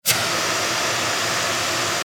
灭火器.MP3